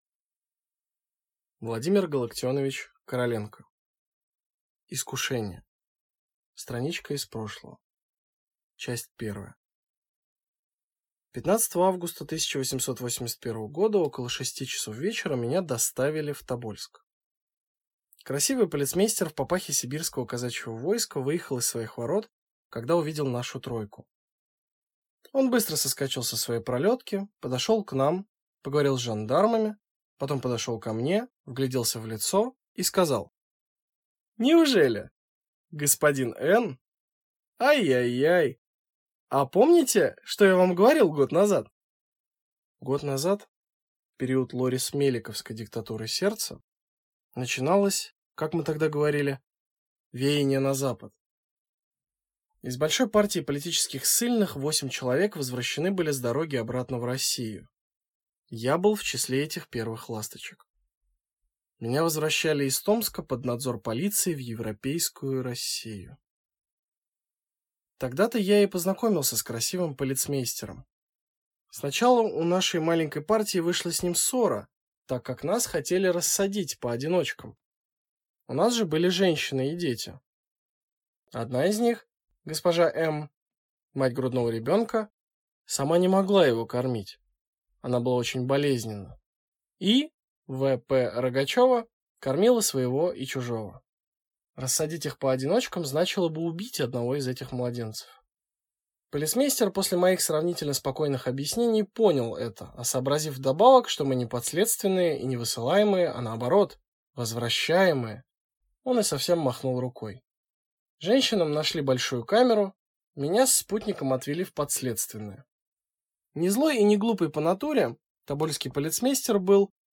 Аудиокнига Искушение | Библиотека аудиокниг